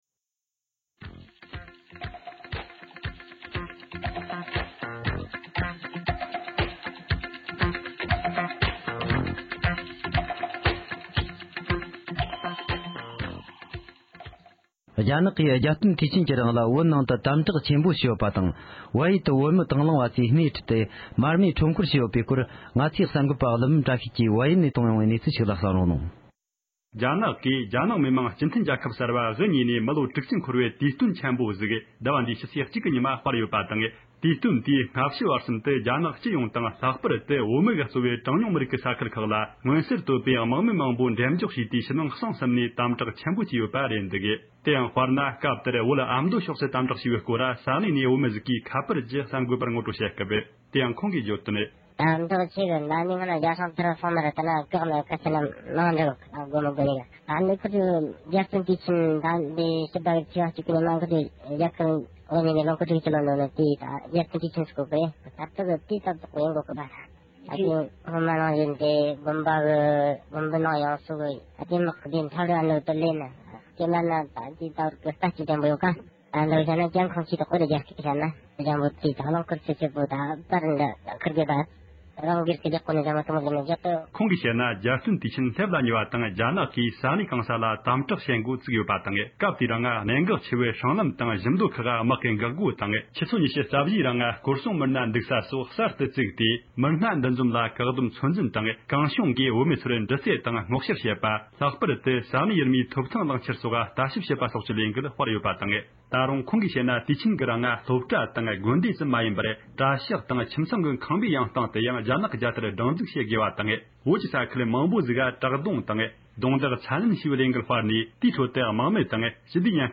སྒྲ་ལྡན་གསར་འགྱུར། སྒྲ་ཕབ་ལེན།
བོད་ཨ་མདོ་ཕྱོགས་ནས་འབྲེལ་ཡོད་བོད་མི་ཞིག་གིས།